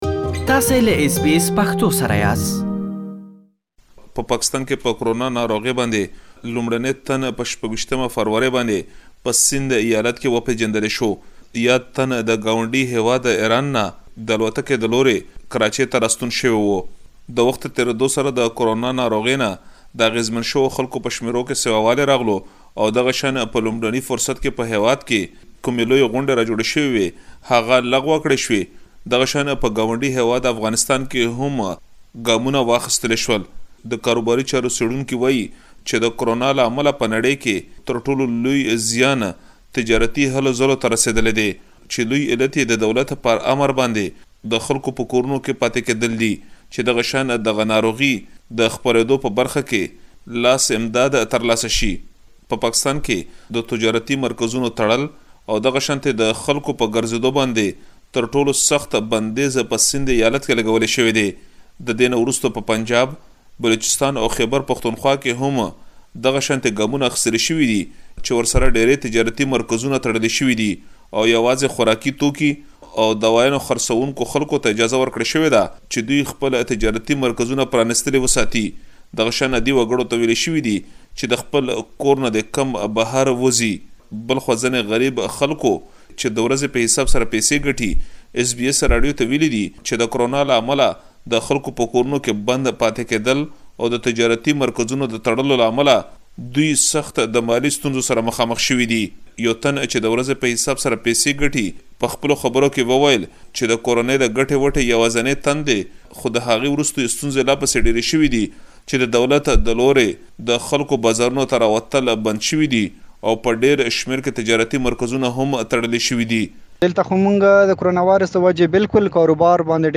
د پيښور ښار اوسيدنکي د کرونا ويروس منفي اغيزو په اړه خبرې کوي.